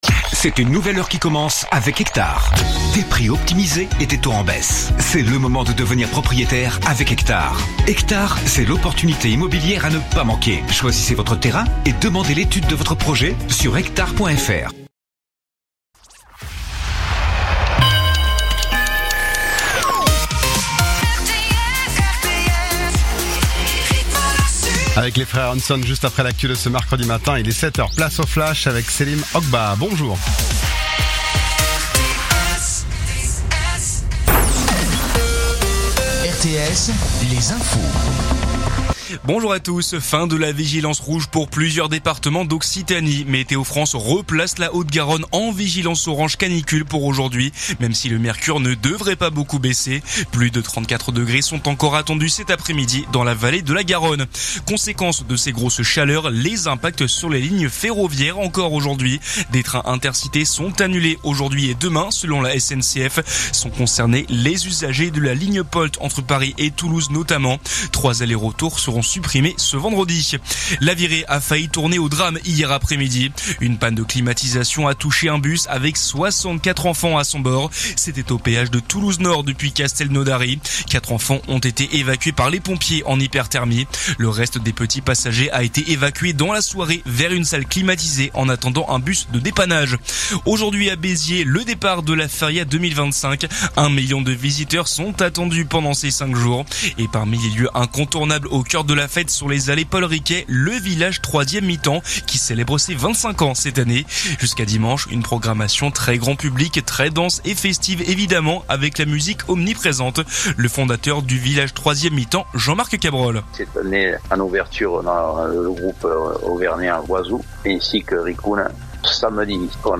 info_mtp_sete_beziers_474.mp3